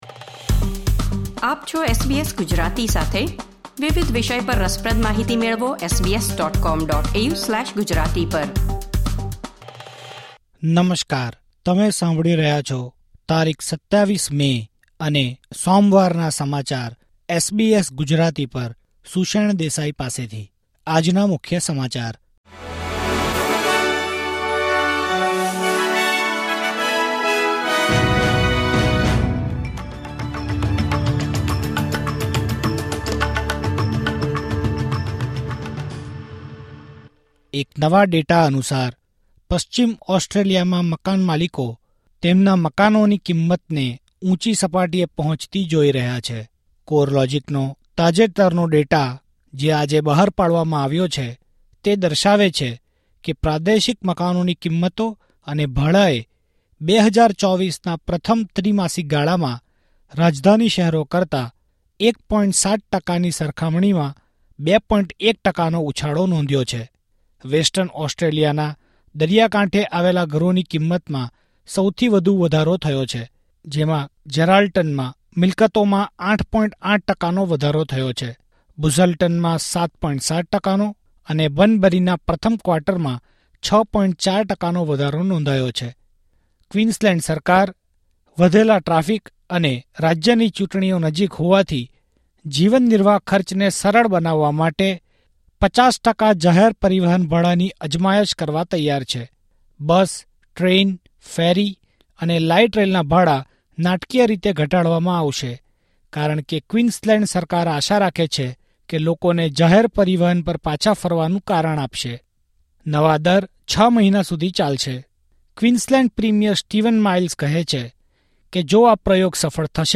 SBS Gujarati News Bulletin 27 May 2024